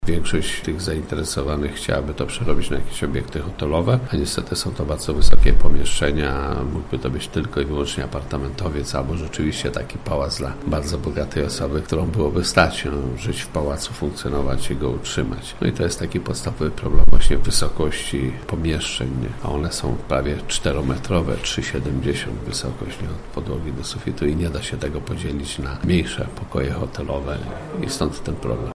– W zasadzie gmina zrobiła wszystko co można, aby zachęcić potencjalnych nabywców. Bez rezultatu – mówi Wiesław Czyczerski, burmistrz Zbąszynka.